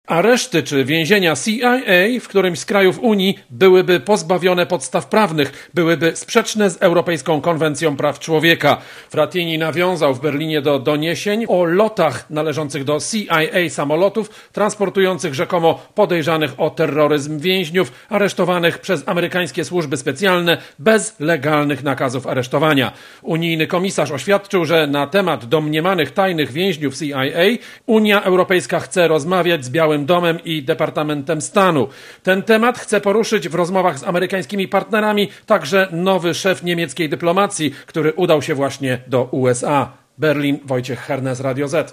Relacja korespondenta Radia ZET